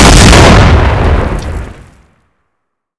explode4.wav